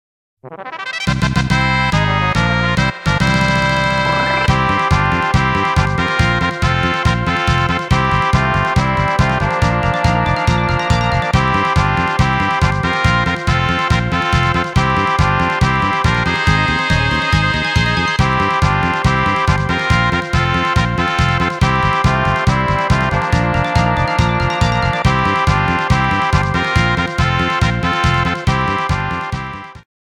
fanfare.m4a